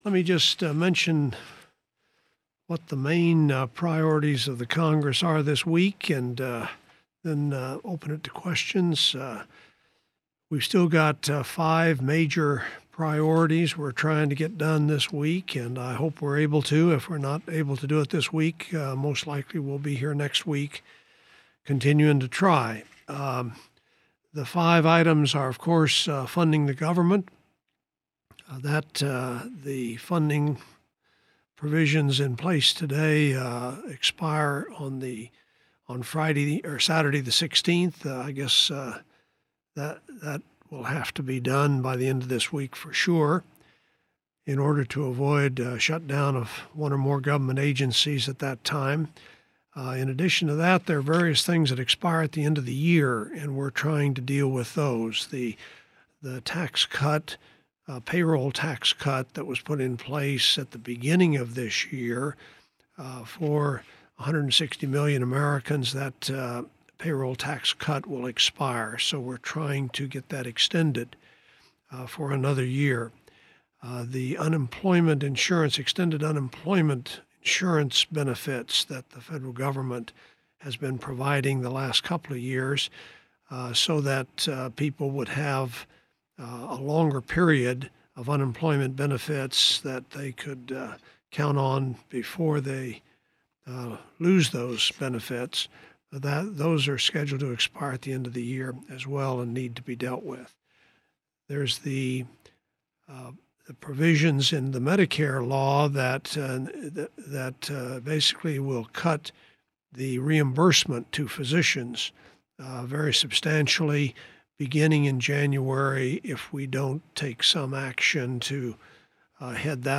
WASHINGTON – U.S. Senator Jeff Bingaman today gave an overview of what the Senate hopes to accomplish before Congress adjourns for the year. Bingaman's remarks can be retrieved and followed: